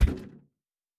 Metal Box Impact 2_4.wav